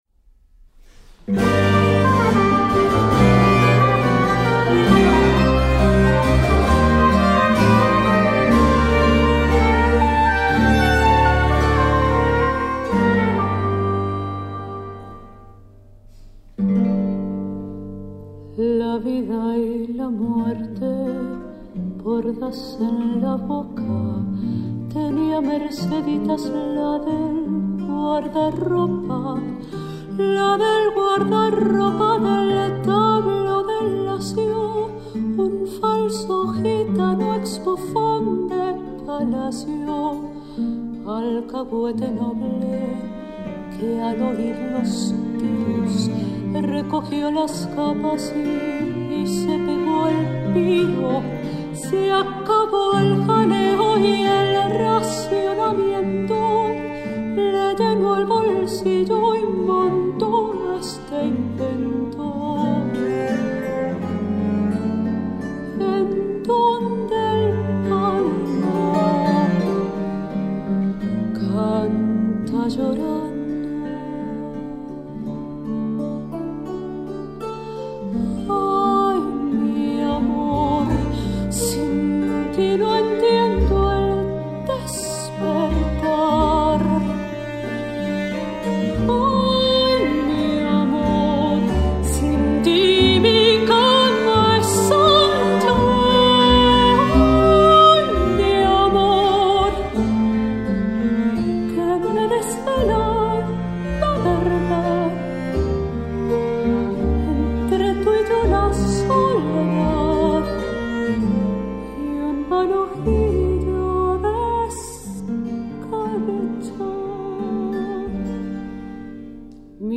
La tonalité  d’ensemble est baroque